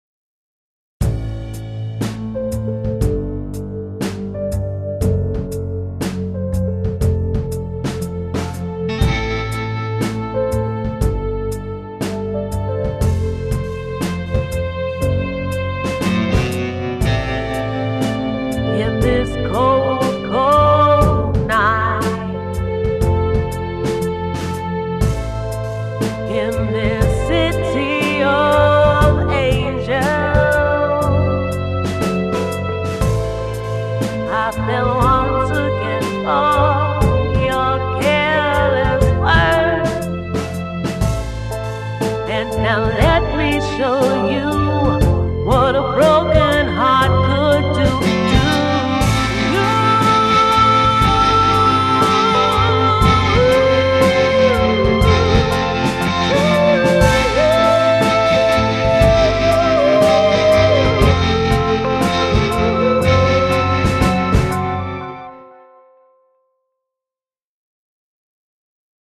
Soft Rock